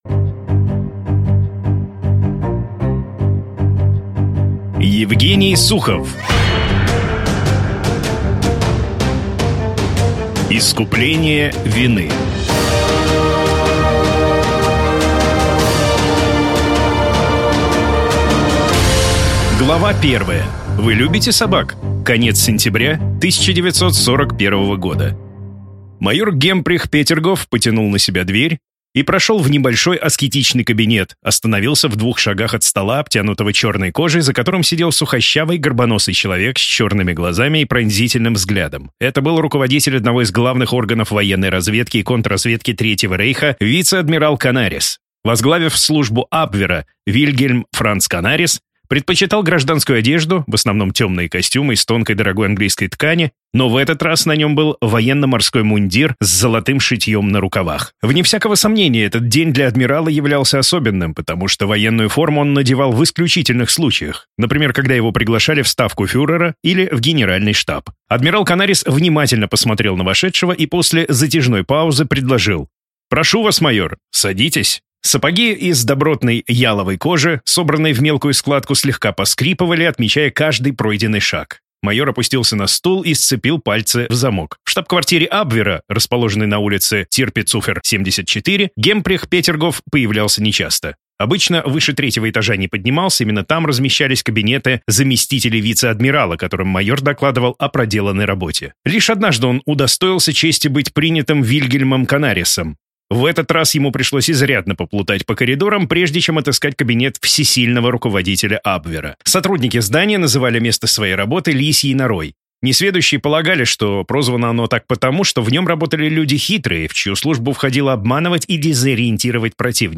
Аудиокнига Искупление вины | Библиотека аудиокниг